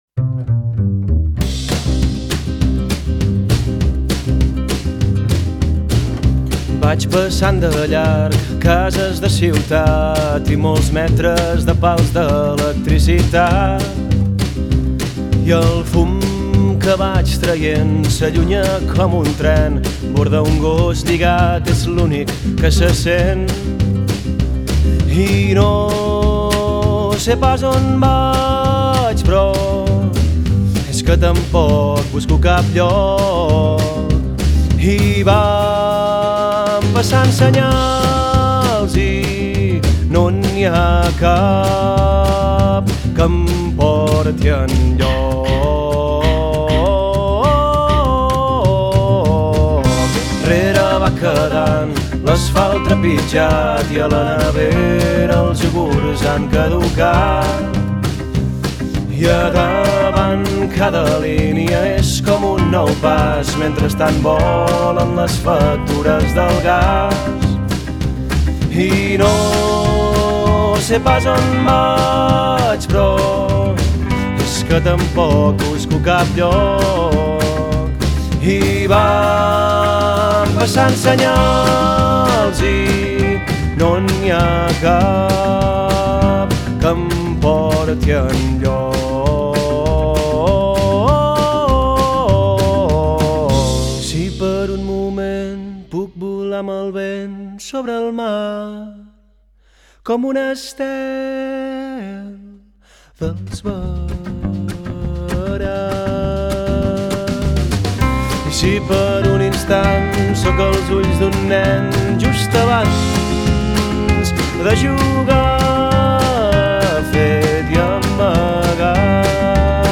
enregistrat als estudis